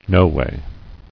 [no·way]